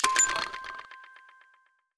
Click (5).wav